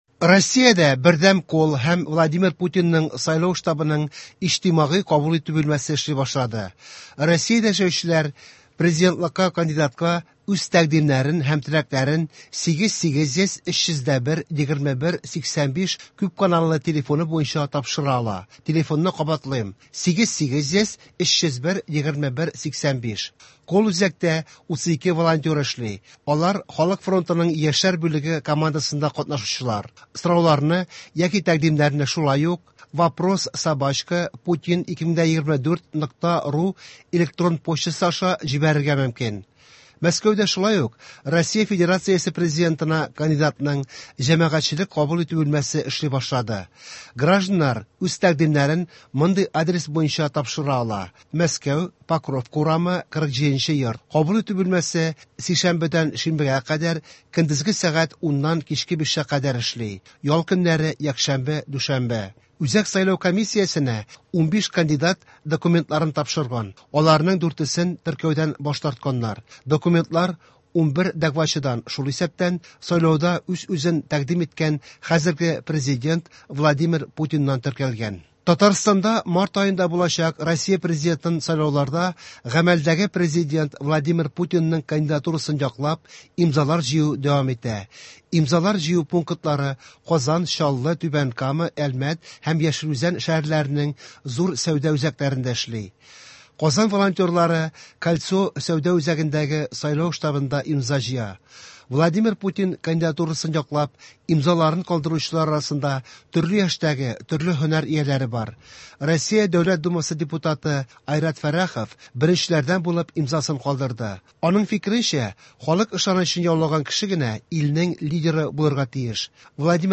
Яңалыклар (10.01.24)